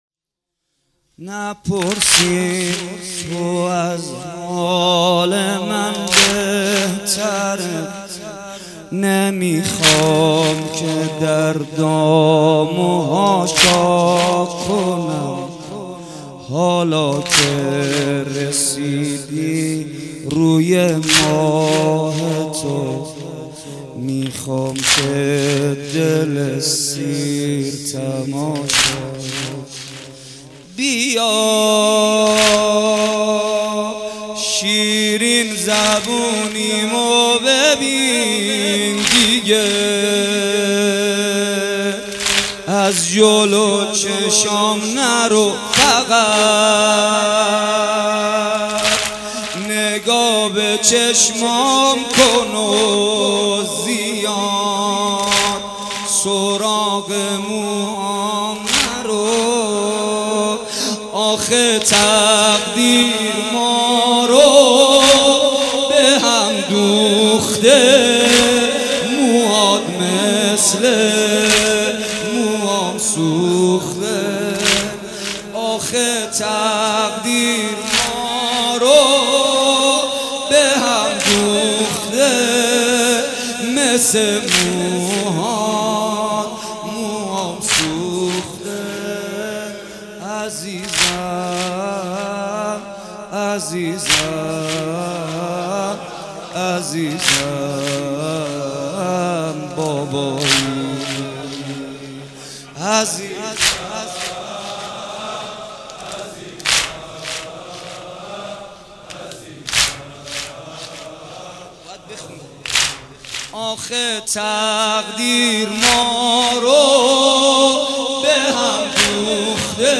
شب سوم محرم الحرام95/ مسجد گیاهی تجریش